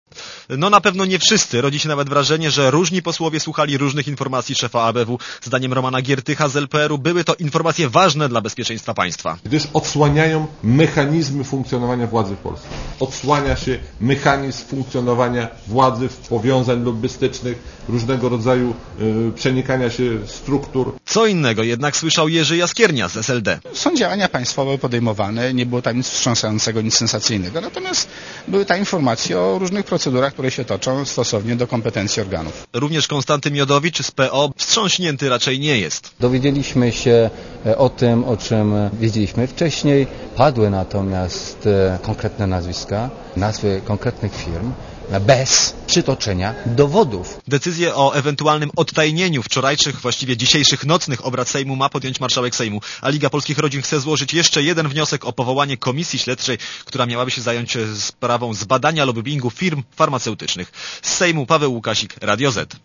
Posłuchaj relacji reportera Radia Zet (248Kb)